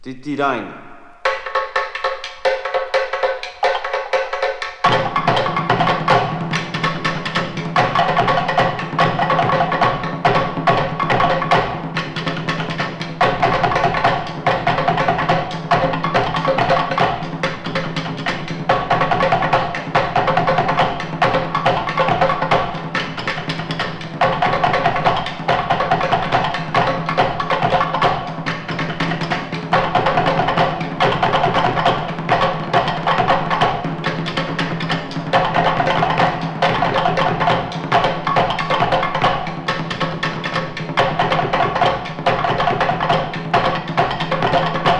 World, Field Recording, Tahiti　USA　12inchレコード　33rpm　Mono